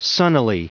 Prononciation du mot sunnily en anglais (fichier audio)
Prononciation du mot : sunnily